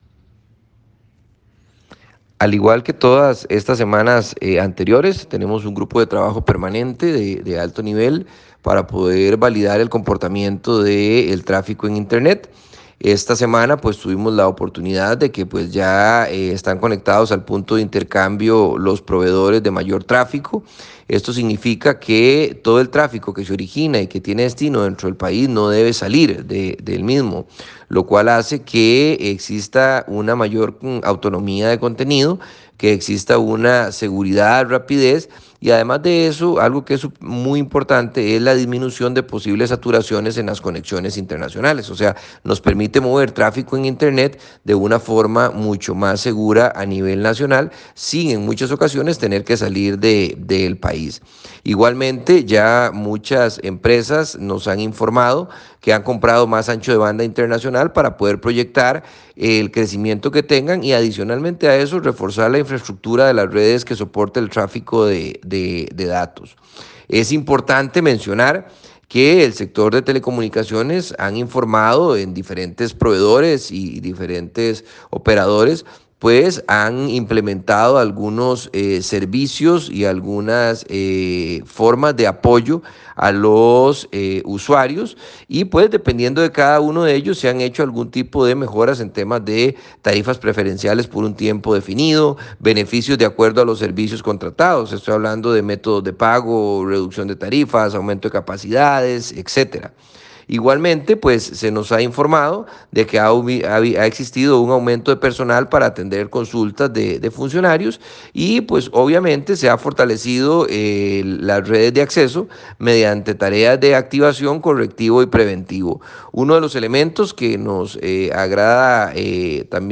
Audio del ministro Luis Adrián Salazar sobre acciones del sector telecomunicaciones ante nuevas medidas por COVID-19